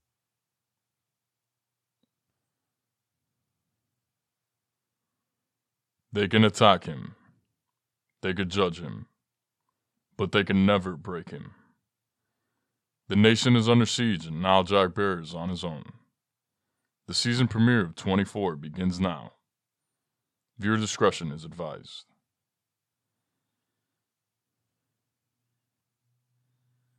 Season Premiere of 24 Prelude Commercial
Young Adult
I have a naturally strong, clear voice with a broad range. My voice is primarily warm and smooth, yet authoritative with an intellectual subtleness.